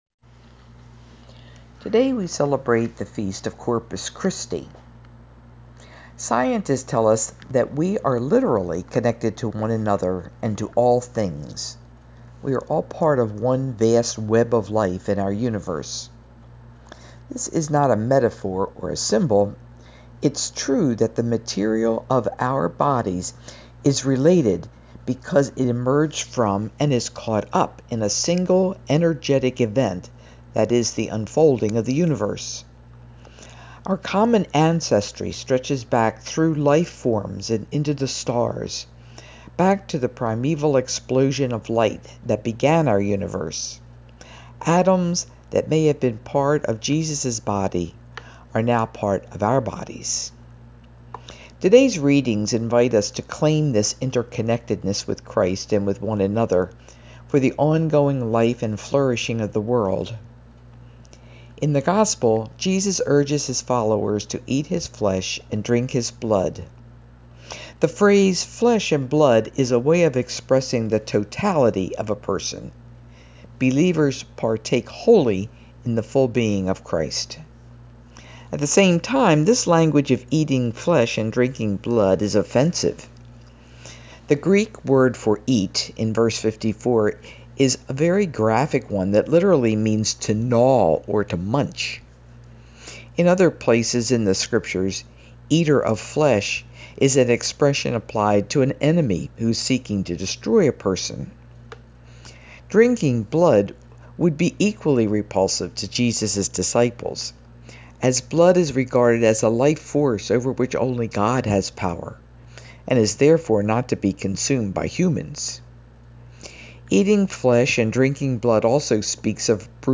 I have recorded my reflection on today's readings..